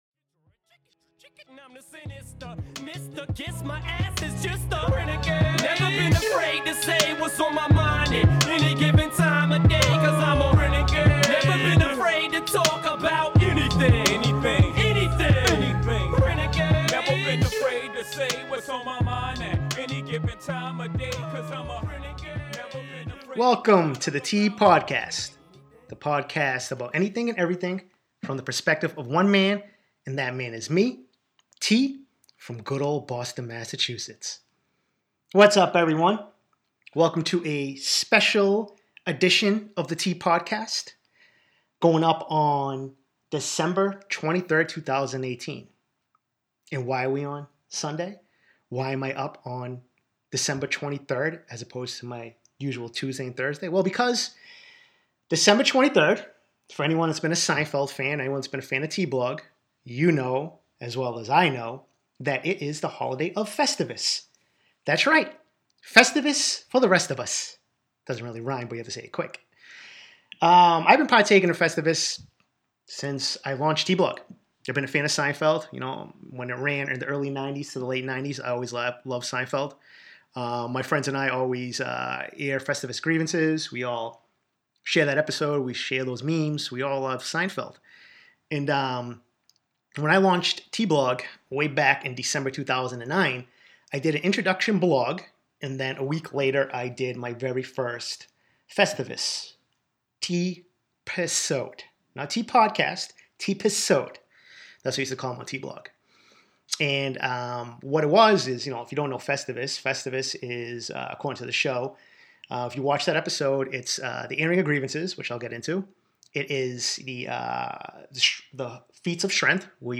My "drunk" and "live" annual airing of grievances for 2018!!!